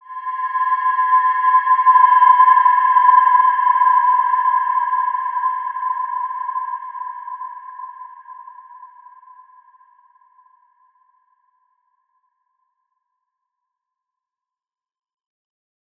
Wide-Dimension-C5-mf.wav